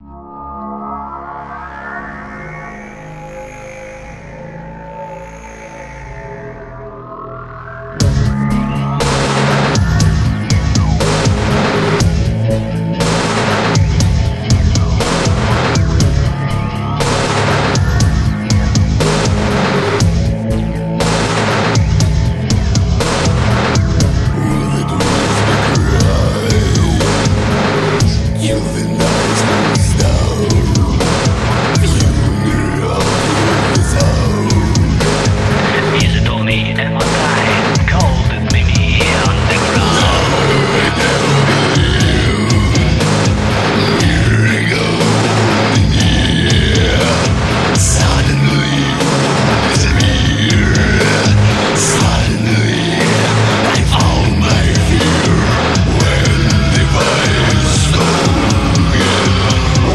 light version